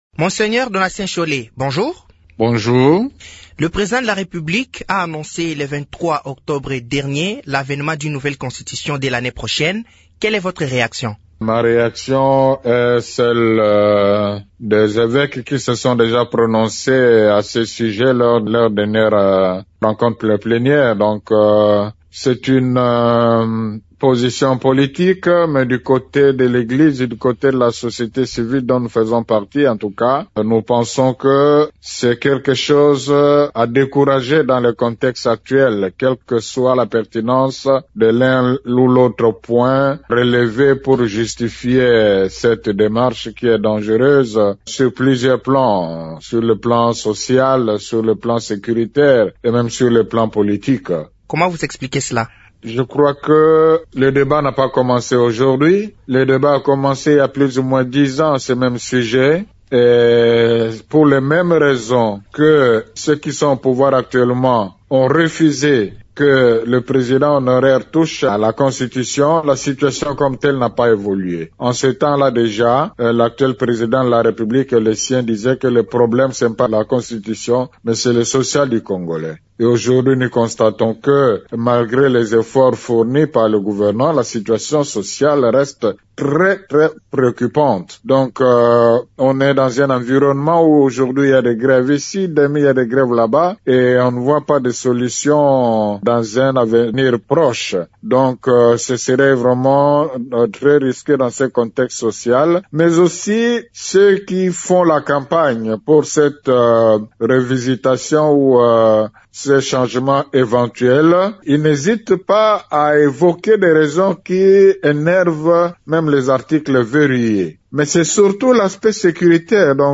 Dans une interview exclusive à Radio Okapi